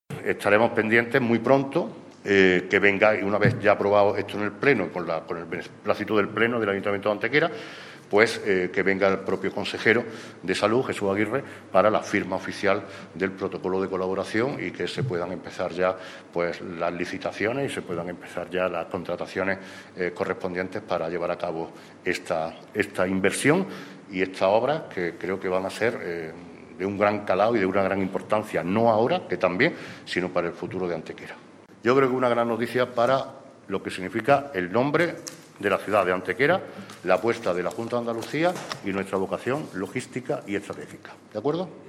El alcalde de Antequera, Manolo Barón, y su primer teniente de alcalde, Juan Rosas, han informado hoy en rueda de prensa de la cesión a la Consejería de Salud de la Junta de Andalucía de una parcela de terreno municipal de 10.000 m2 de extensión anexa al aparcamiento general del Hospital Comarcal y con salida directa hacia las autovías A-92 y A-45 para que pueda albergar dos nuevas instalaciones estratégicas del Servicio Andaluz de Salud.
Cortes de voz